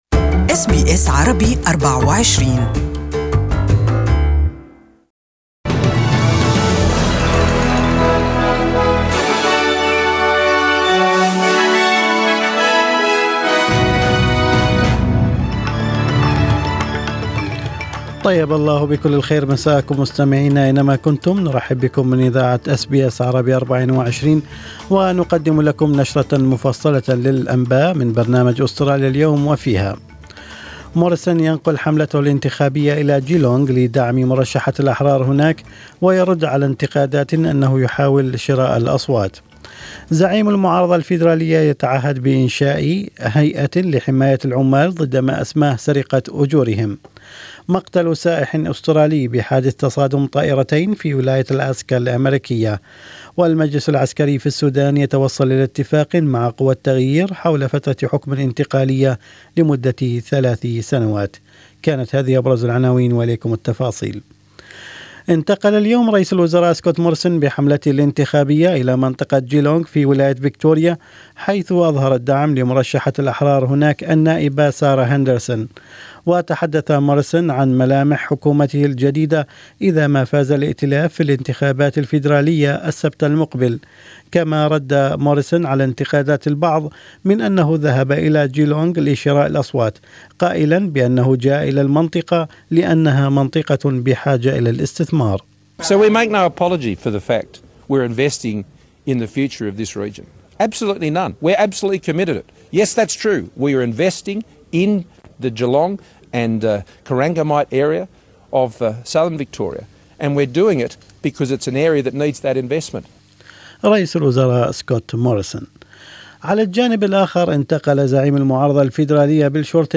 Evening News Bulletin: Scott Morrison back in his happy place